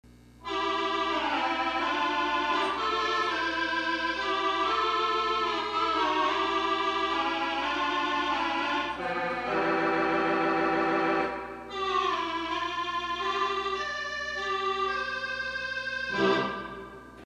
Sax.mp3